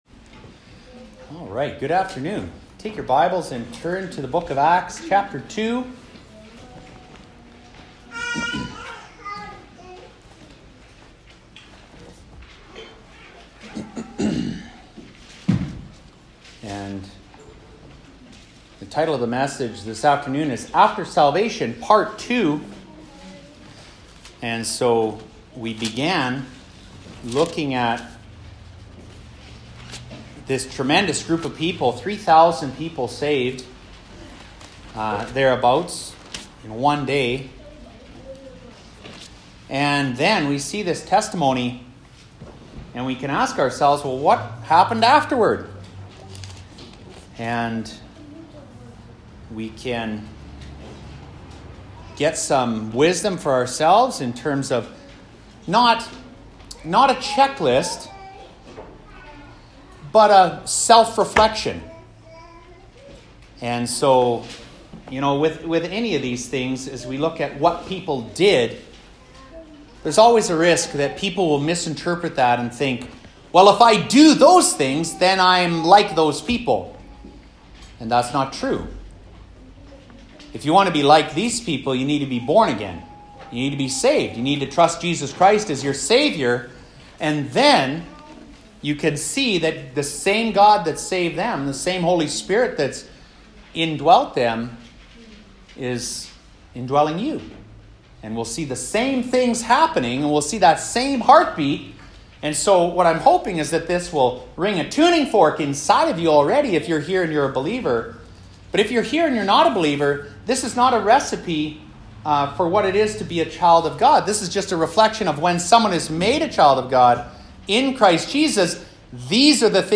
Sermons | Harvest Baptist Church